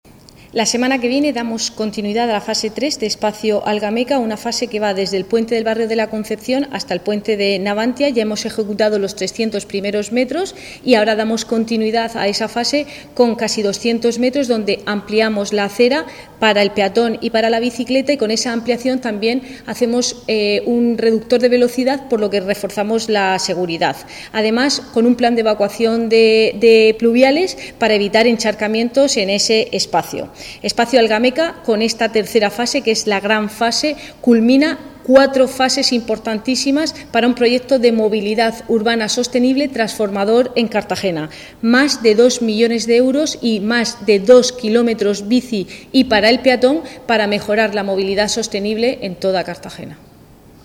Enlace a Declaraciones de Cristina Mora sobre Espacio Algameca